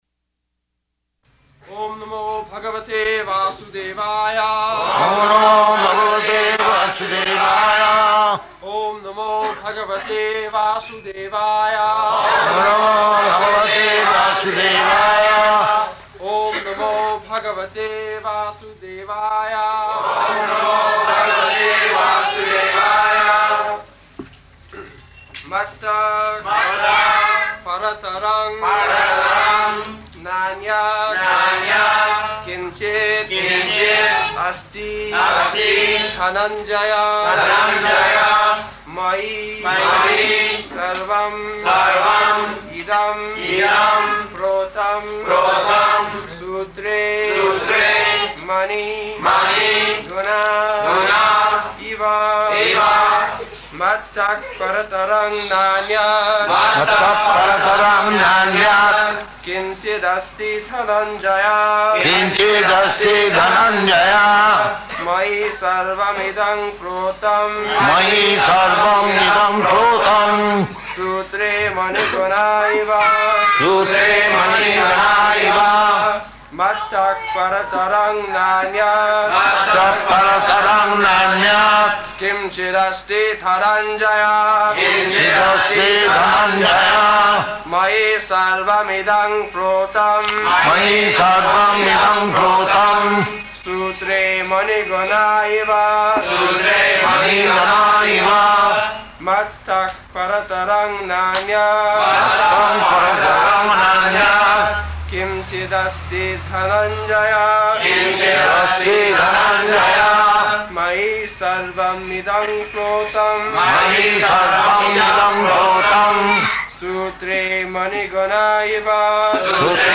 February 22nd 1974 Location: Bombay Audio file
[Prabhupāda and devotees repeat] [leads chanting of verse, etc.]